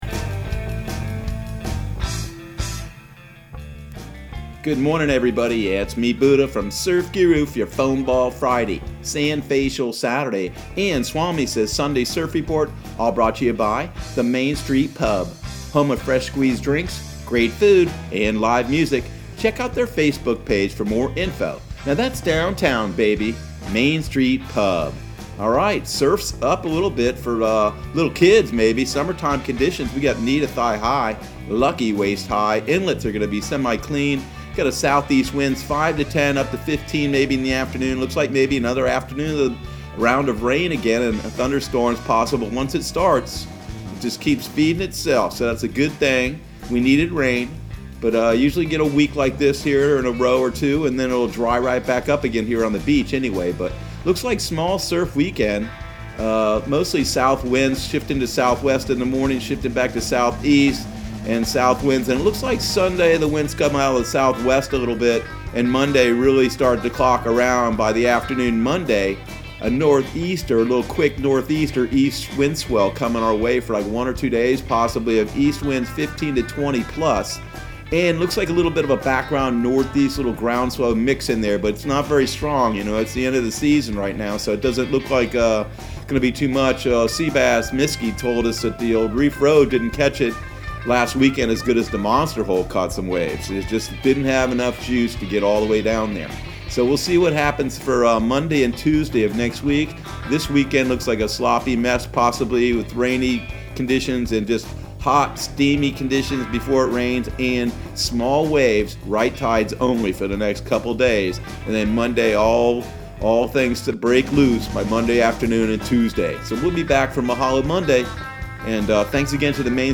Surf Guru Surf Report and Forecast 05/29/2020 Audio surf report and surf forecast on May 29 for Central Florida and the Southeast.